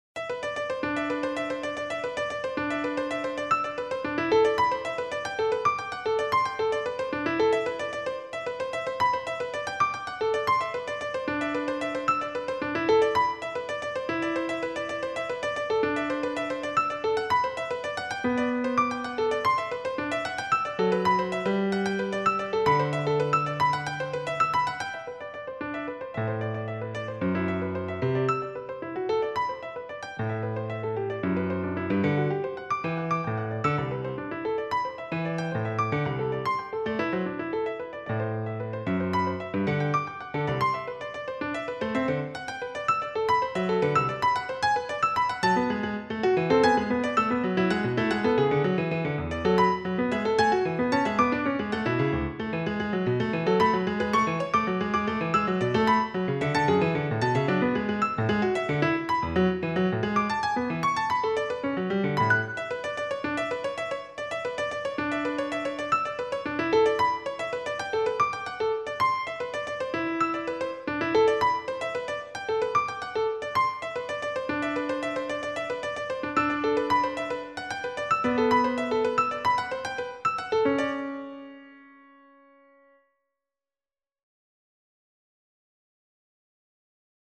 meta.stasis (2005 - In Progress) electronic media
The sounds of meta.stasis were all created inside the context of computer music programs on both Macintosh and Windows computers.